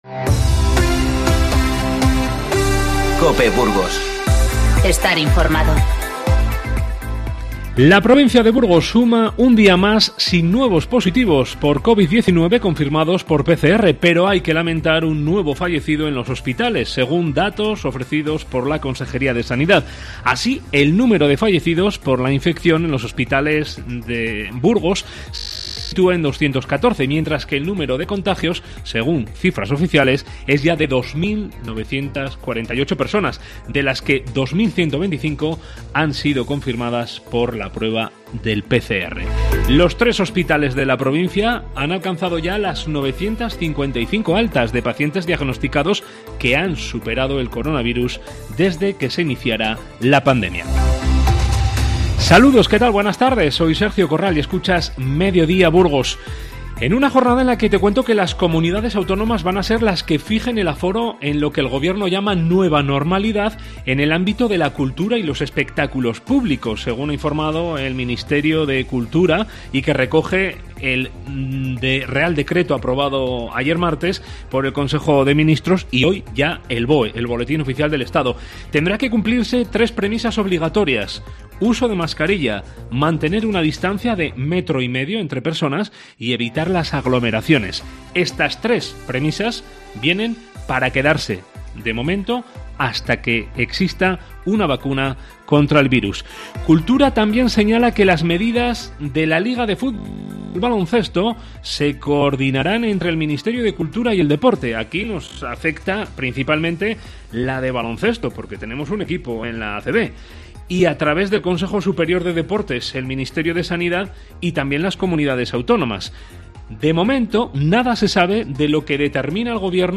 Informativo 10/6